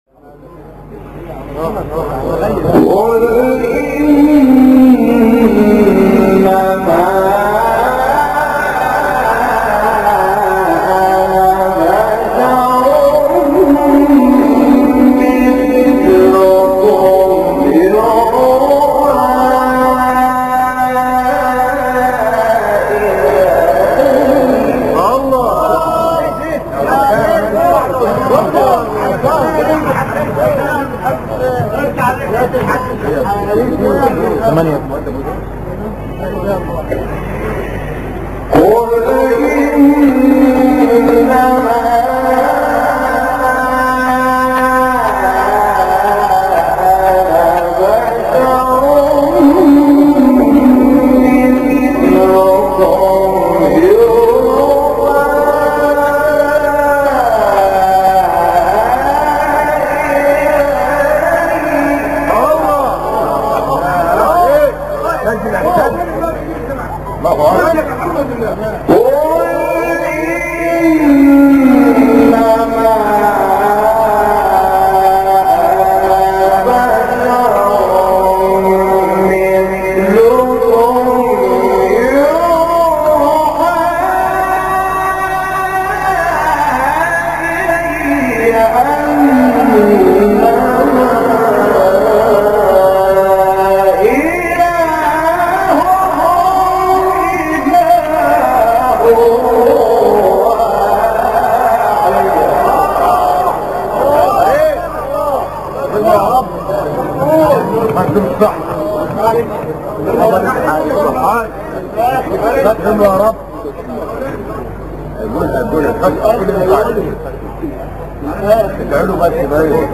آیه 110 سوره کهف استاد محمود شحات | نغمات قرآن | دانلود تلاوت قرآن